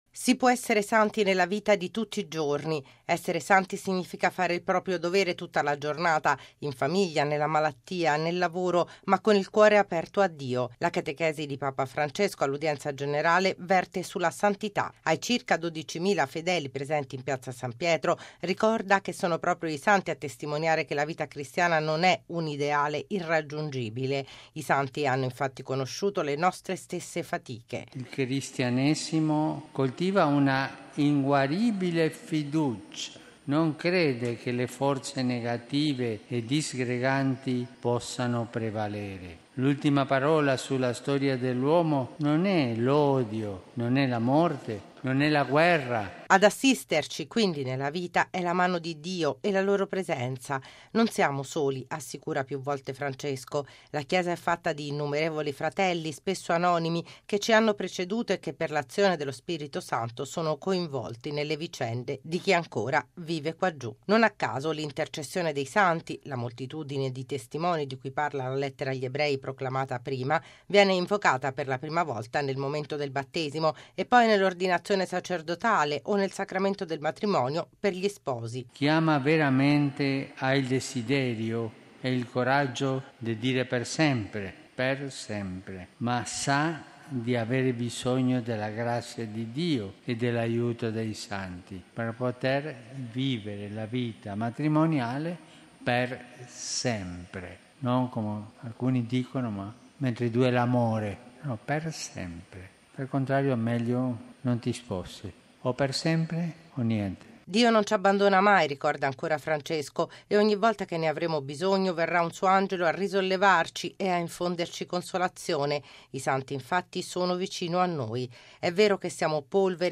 Così Papa Francesco nella catechesi stamani all’udienza generale in Piazza San Pietro. Il Papa prosegue il ciclo di meditazioni sulla speranza cristiana mettendo in evidenza come i santi siano testimoni e compagni di speranza.